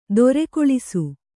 ♪ dorekoḷisu